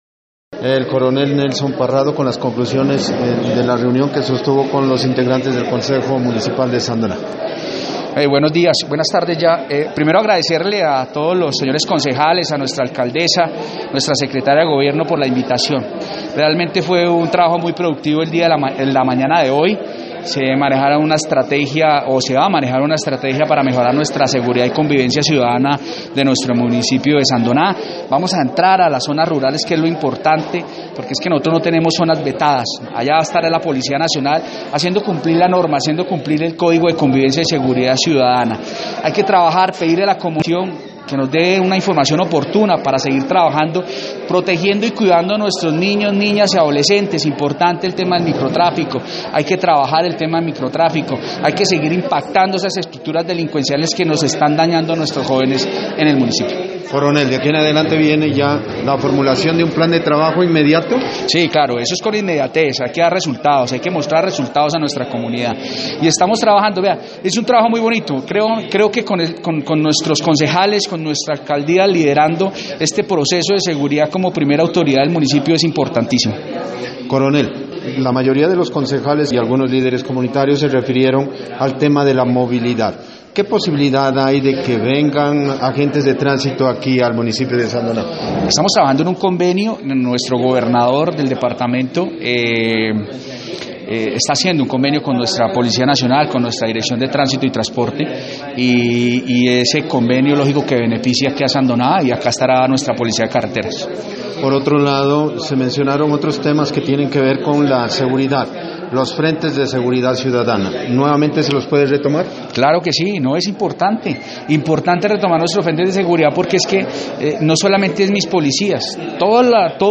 Entrevista con el coronel Nelson Parrado Mora: